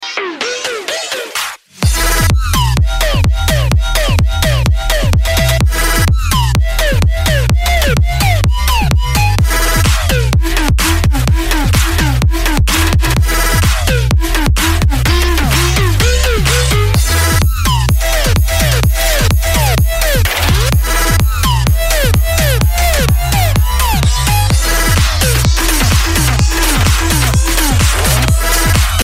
Громкие Рингтоны С Басами » # Рингтоны Без Слов
Рингтоны Электроника